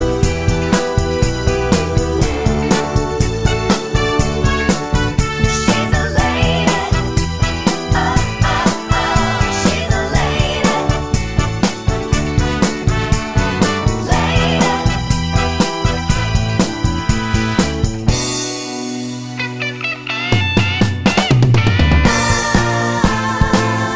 With Live Ending Pop (1960s) 2:56 Buy £1.50